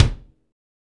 Index of /90_sSampleCDs/Northstar - Drumscapes Roland/KIK_Kicks/KIK_H_H Kicks x
KIK H H K01R.wav